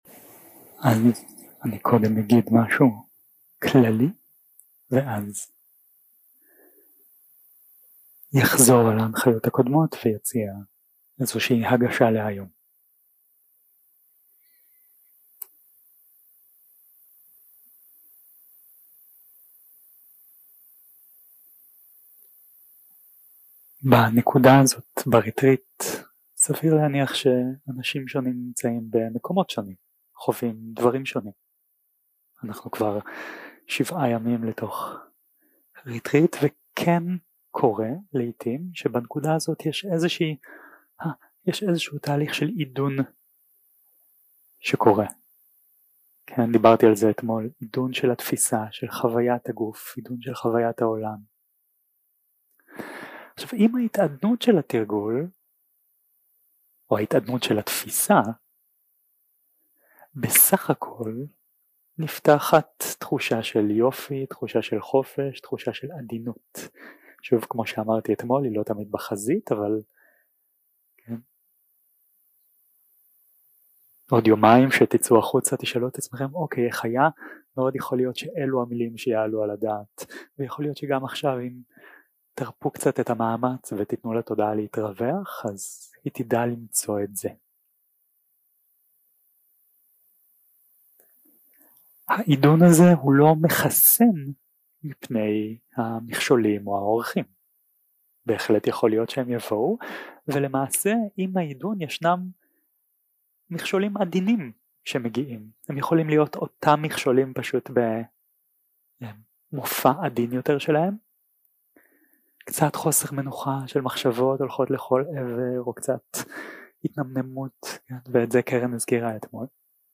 יום 7 - הקלטה 15 - בוקר - הנחיות למדיטציה - תרגול עם ארבעת היסודות 0:00 0:00 סוג ההקלטה: Dharma type: Guided meditation שפת ההקלטה: Dharma talk language: Hebrew